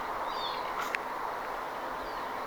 tuollainen räkättirastaan ääni
tuollainen_rakattirastaan_aani.mp3